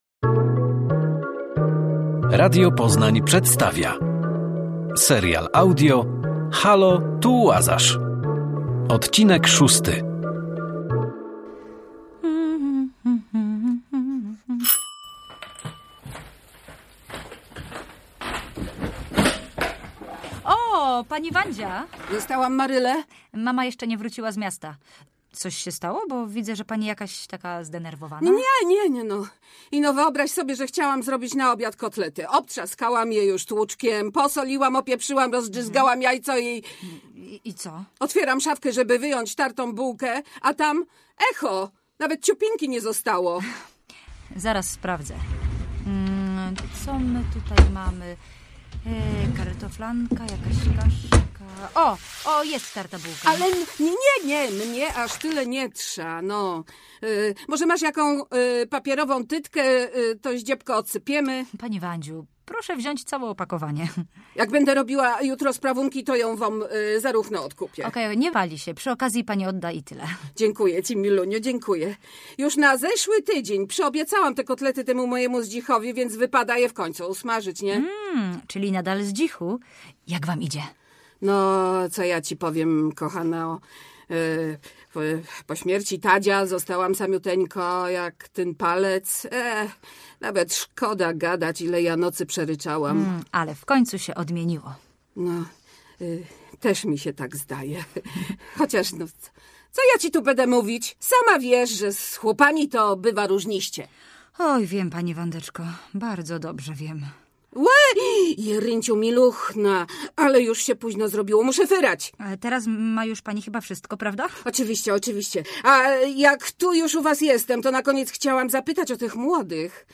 Serial audio Radia Poznań